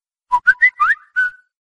Kategorien: Whatsapp